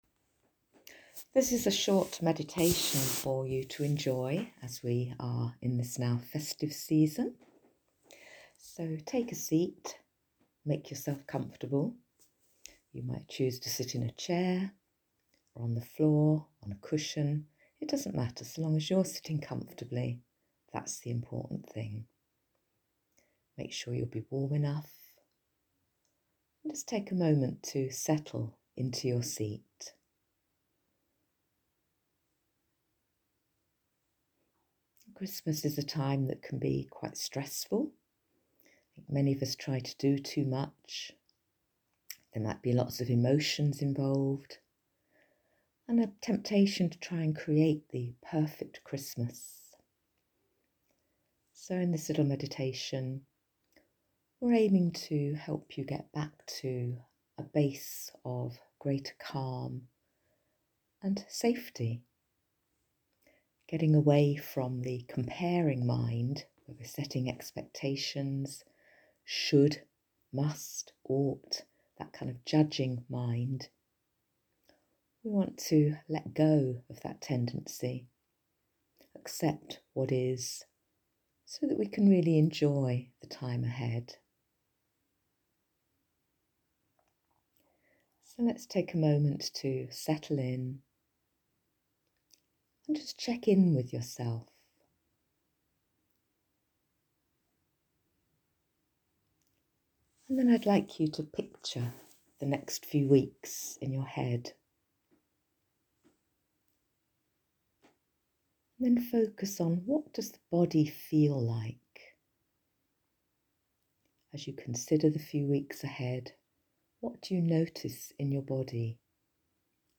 Christmas Meditation
Short meditation for you to enjoy during this busy time